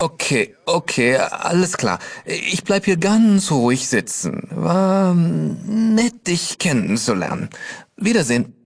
Kategorie:Fallout 2: Audiodialoge Du kannst diese Datei nicht überschreiben.